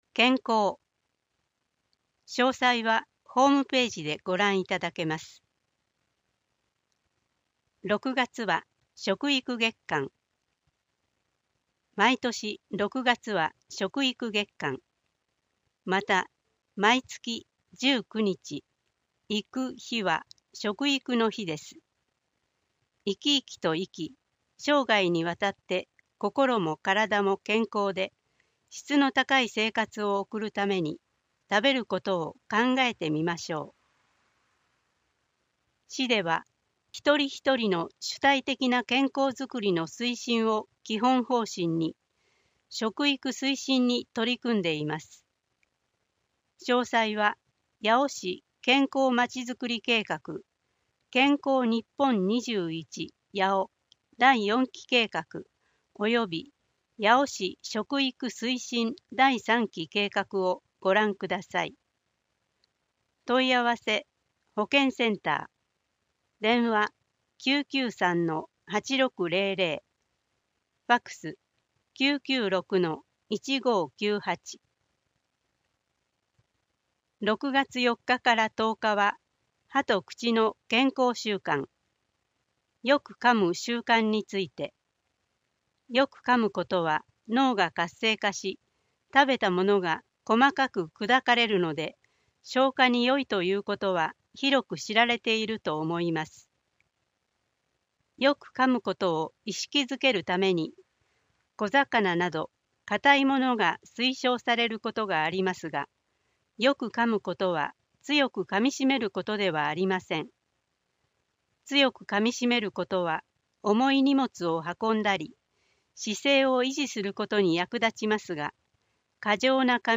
声の市政だより 令和5年6月号<音声ファイル版>
なお、視覚障がいのある人などのために、市政だよりの記事を抜粋した「点字広報」と「声の市政だより」を毎月1回発行しています。